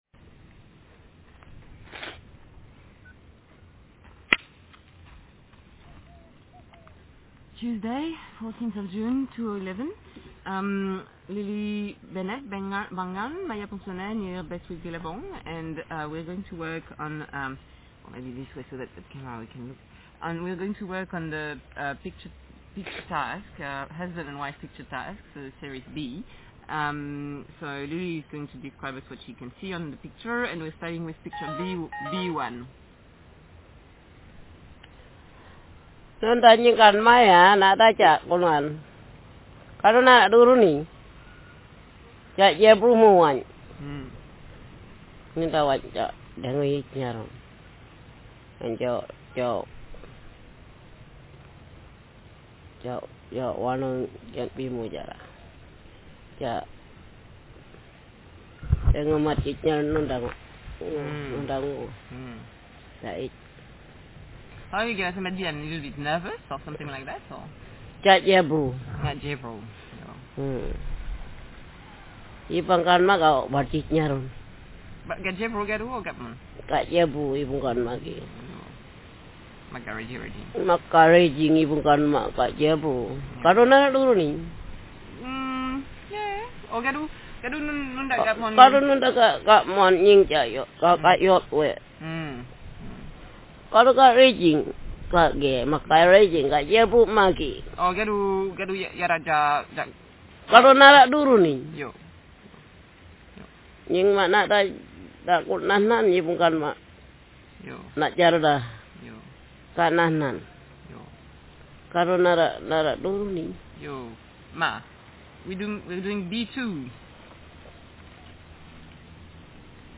Speaker sexf
Text genrestimulus retelling